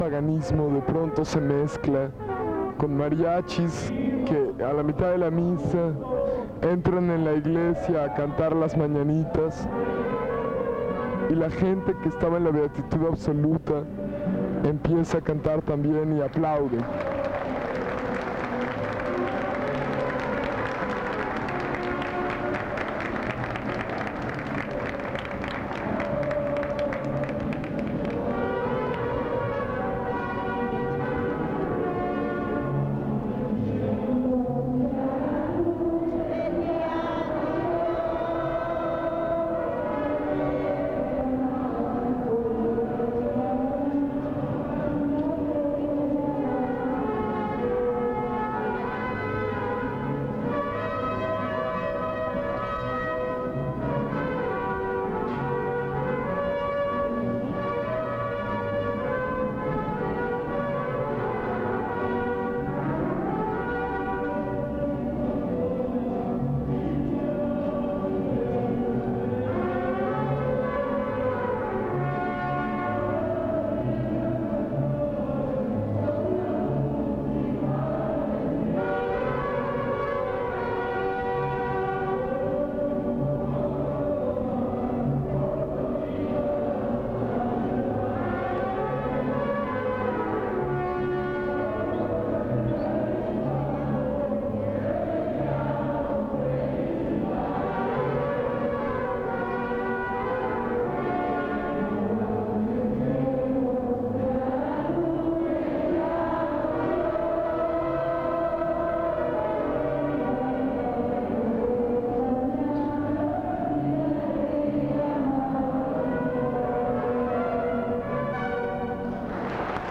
03 Mañanitas en el Santuario de la Candelaria
Fiesta de La Candelaria: investigación previa